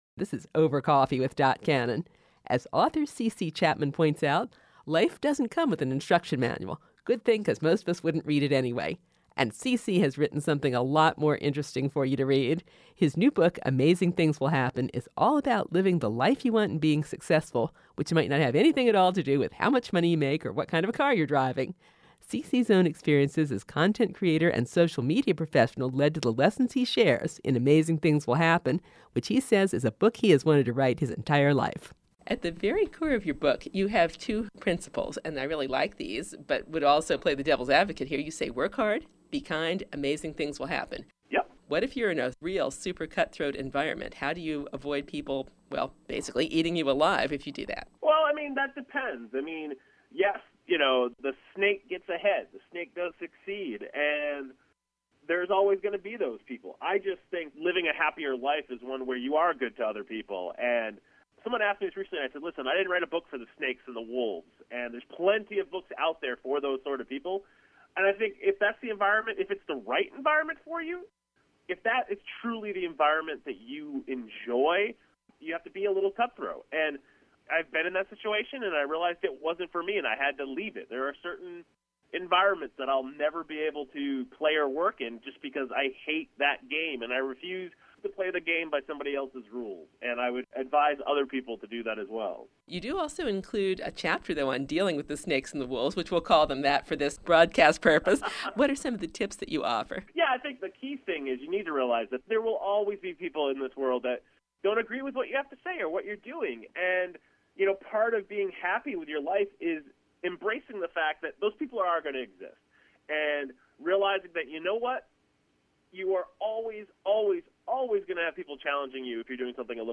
Interview, Part Two